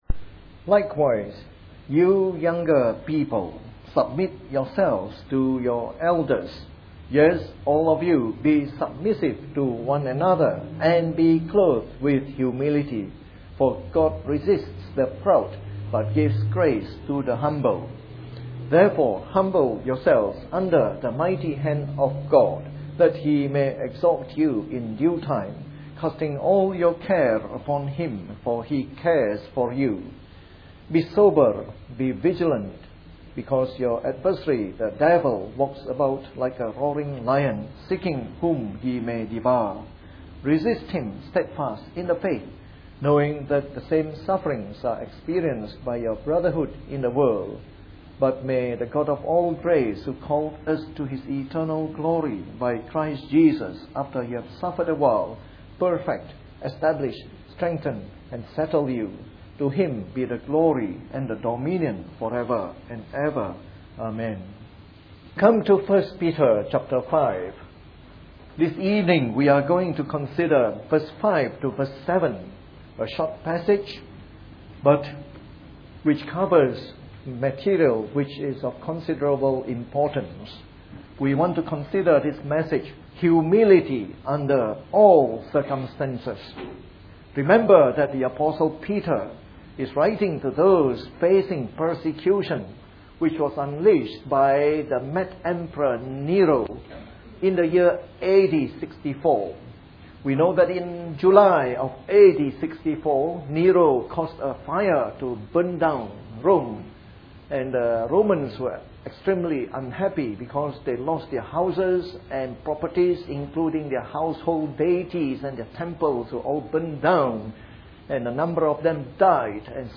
MP3 Sermons – 2012